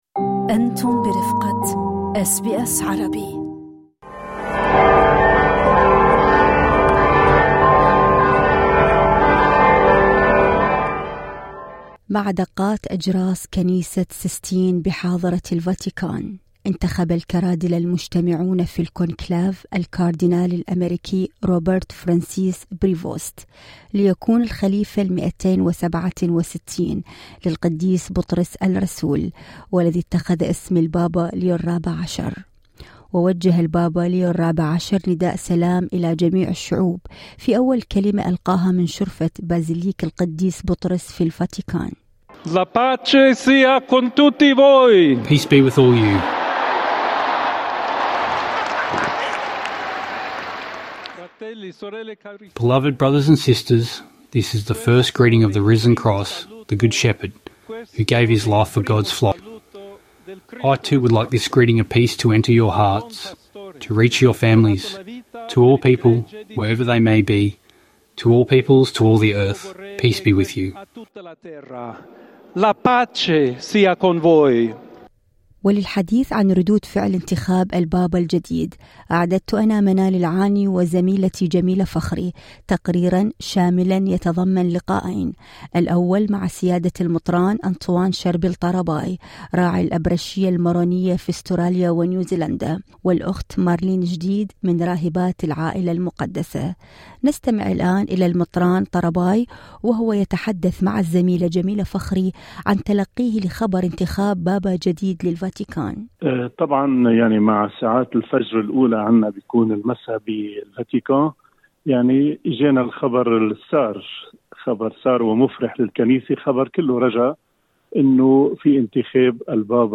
تقريراً شاملاً يتضمن لقاءين